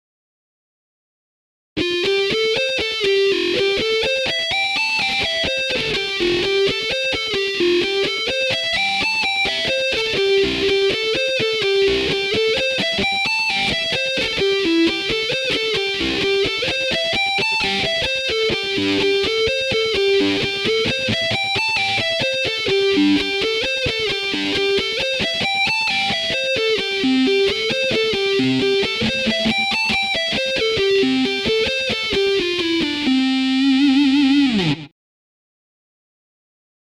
完全ピッキングで演奏するか、ハンマリング＆プリングで演奏するかは個人の判断でよいでしょう。
こういった弦とびフレーズを難なくこなすことが出来てくると、演奏の幅は広がります。